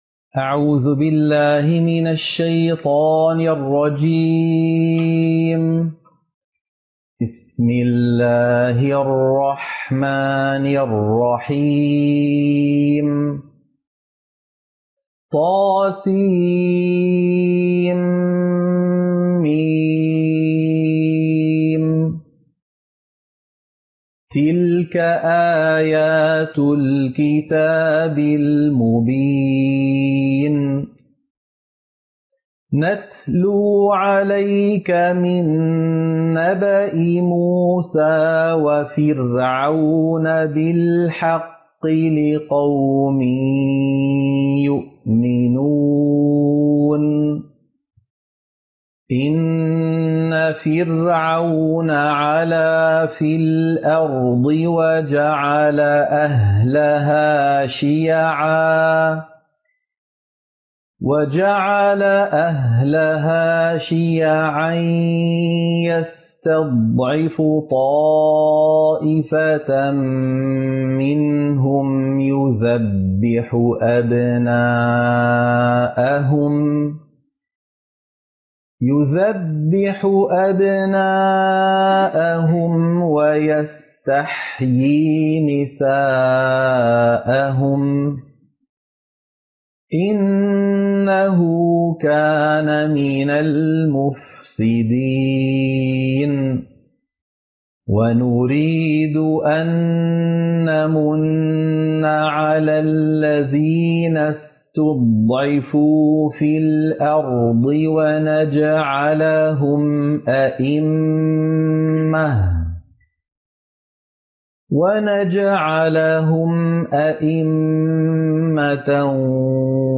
سورة القصص - القراءة المنهجية